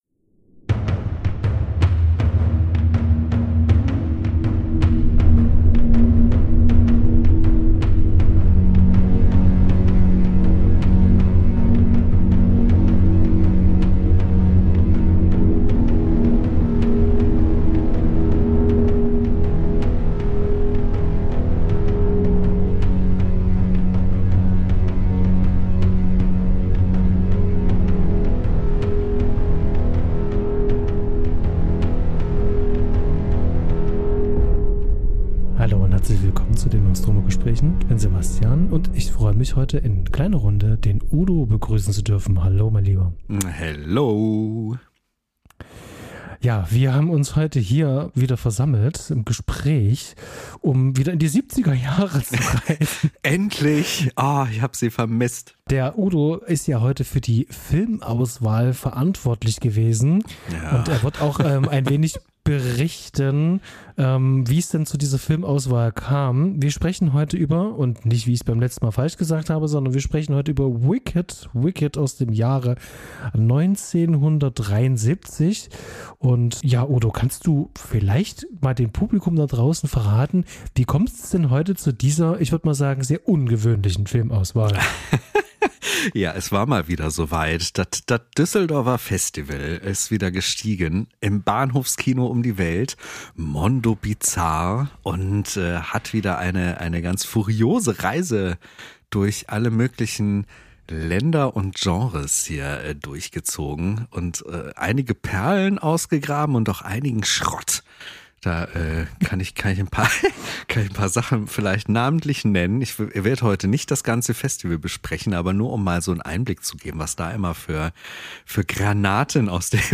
mal im Duo, mal im Trio und ab und an mit erlesenen GästInnen über ihre große Leidenschaft - Filme.